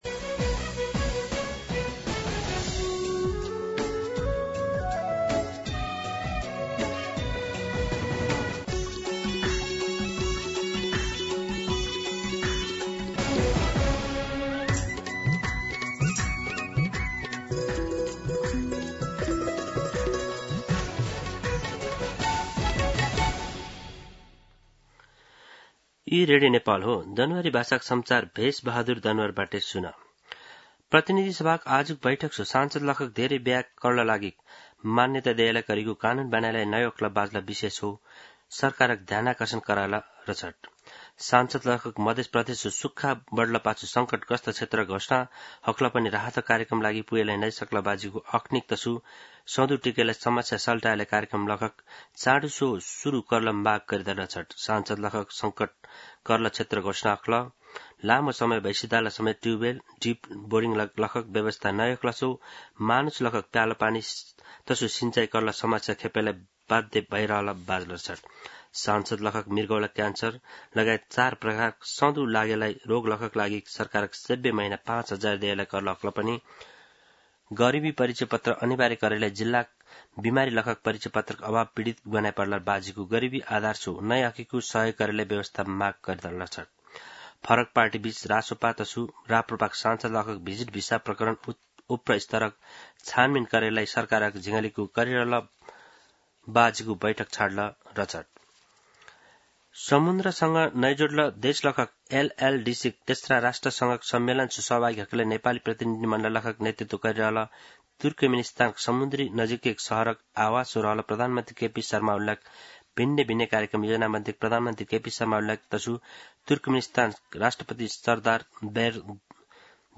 दनुवार भाषामा समाचार : २१ साउन , २०८२
Danuwar-News-04-21.mp3